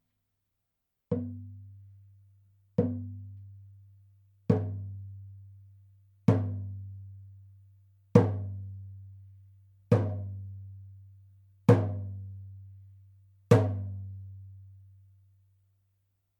ネイティブ アメリカン（インディアン）ドラム NATIVE AMERICAN (INDIAN) DRUM 14インチ（deer 鹿）
ネイティブアメリカン インディアン ドラムの音を聴く
乾いた張り気味の音です